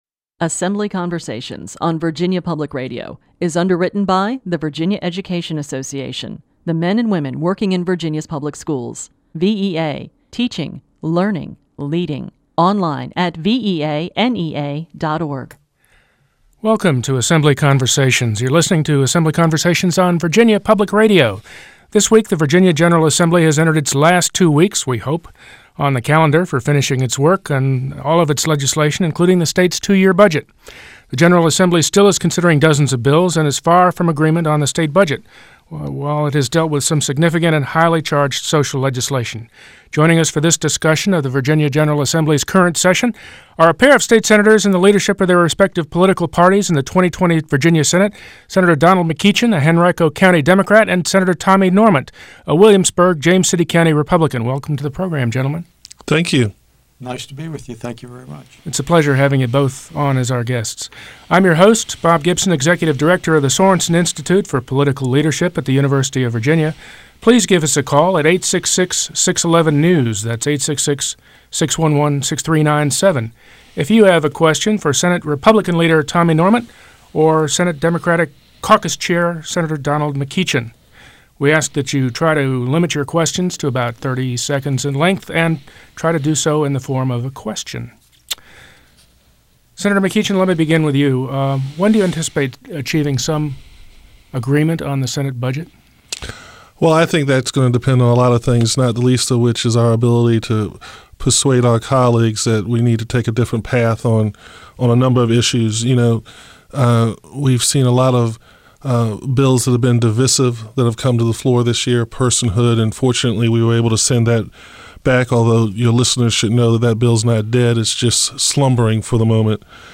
The Virginia General Assembly is in the process of writing a new two-year state budget and striving to complete its business by March 10th. On this episode of Assembly Conversations, a discussion on how your state dollars are being spent.